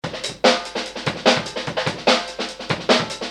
模糊鼓的突破7
标签： 140 bpm Funk Loops Drum Loops 286.08 KB wav Key : Unknown
声道单声道